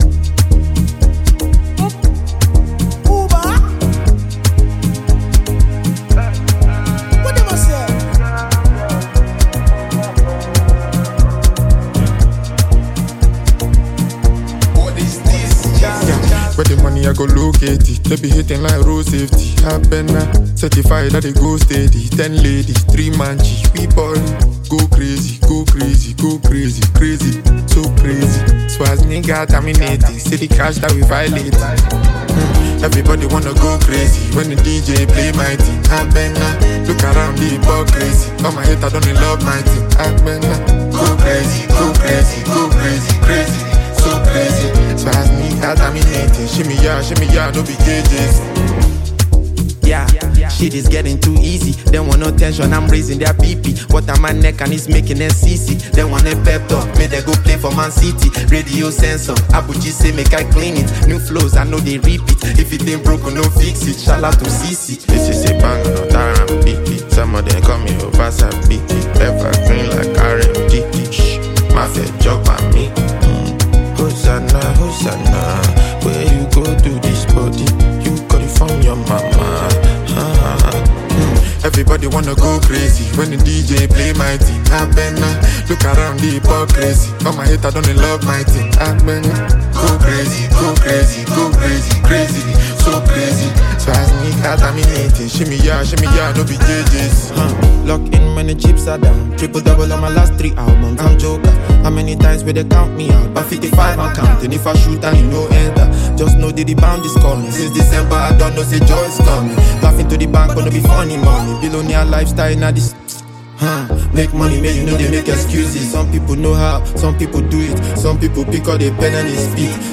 Nigerian rap sensation